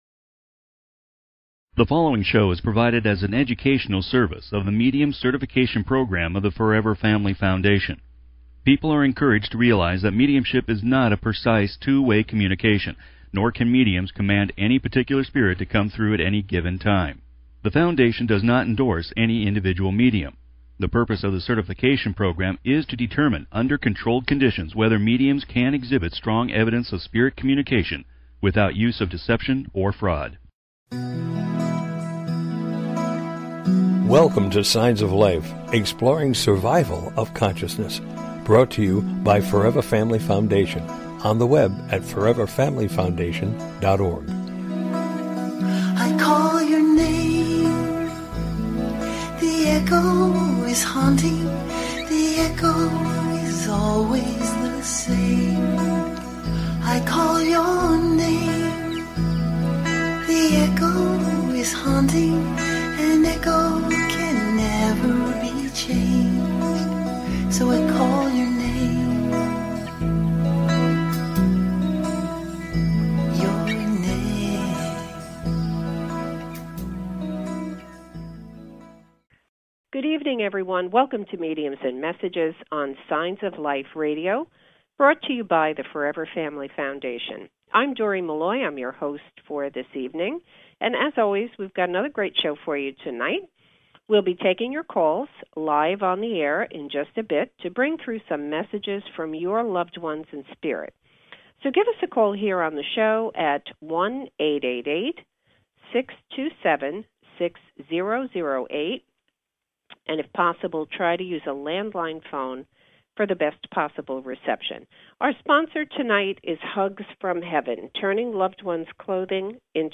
Interviewing guest medium